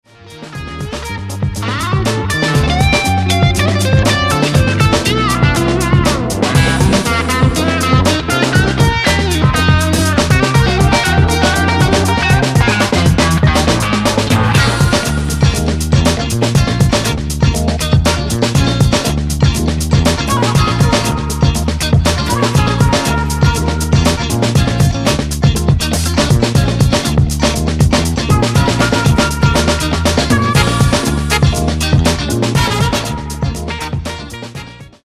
Genere:   Funky | Soul